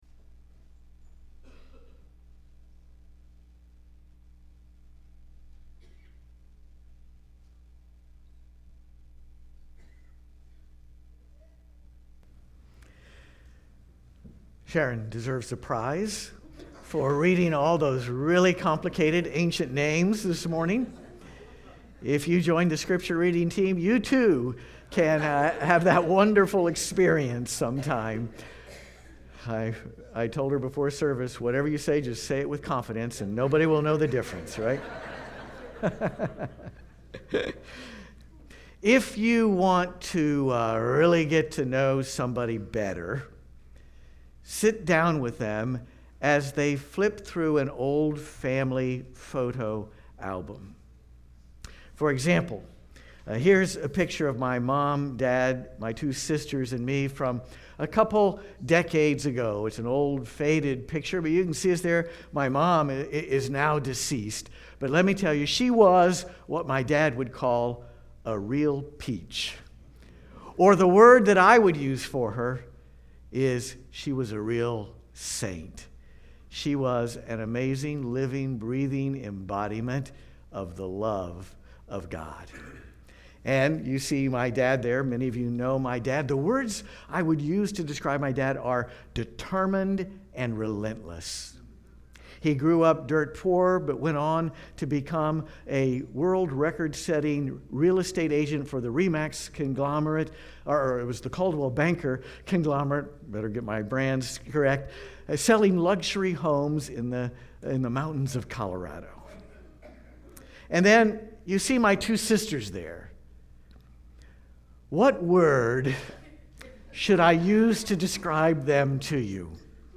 Watch the entire Worship Service